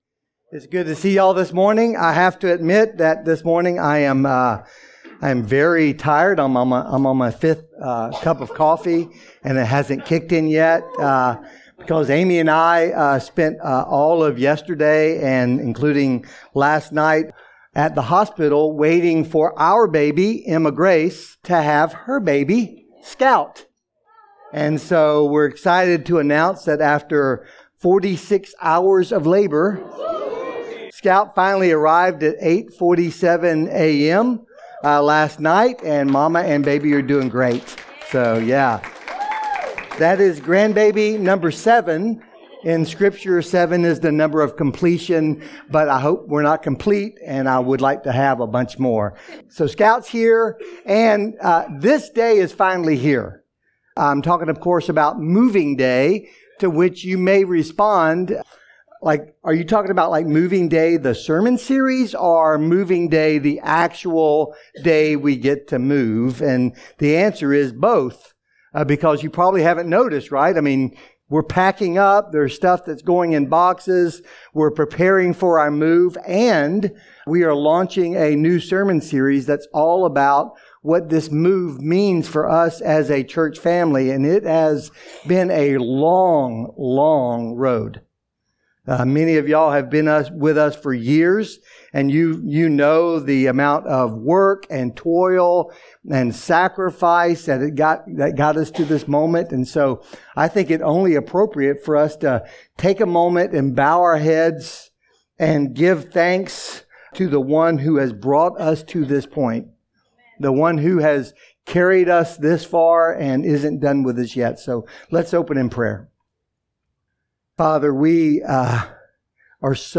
Weekly sermons from Hutto Bible Church's Sunday worship gathering.